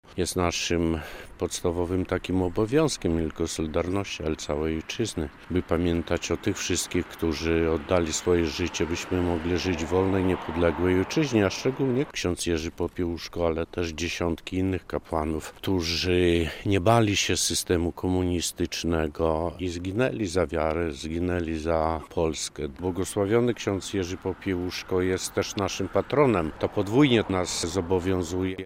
relacja
Z tej okazji działacze podlaskiej Solidarności uczcili pamięć ks. Popiełuszki składając kwiaty pod jego pomnikiem w Białymstoku.